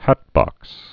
(hătbŏks)